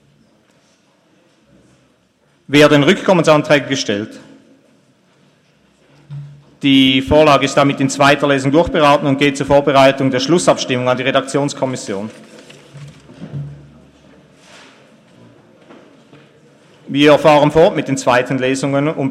Session des Kantonsrates vom 19. und 20. Februar 2018